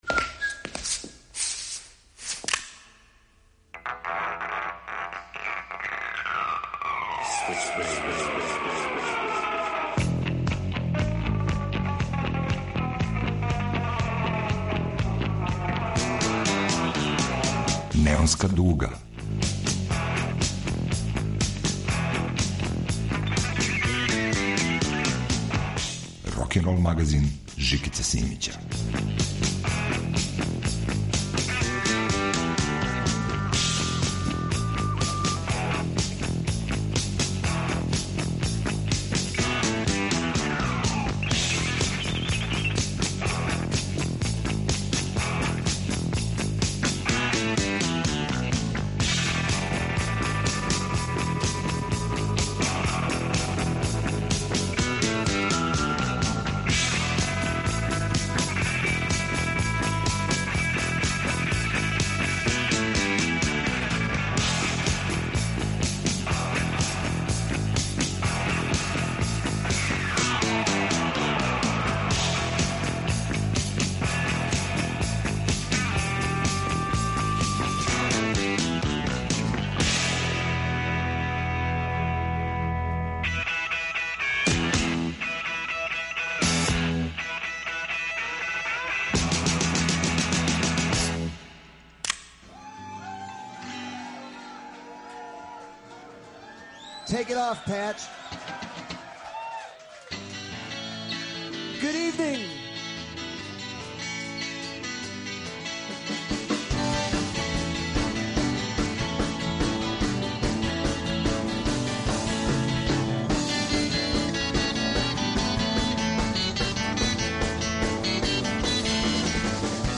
Рокенрол као музички скор за живот на дивљој страни. Вратоломни сурф кроз време и жанрове.
На репертоару Неонске дуге ове недеље неосоул, неопсиходелија, постмодерна меланхолија и блуз фундаментализам.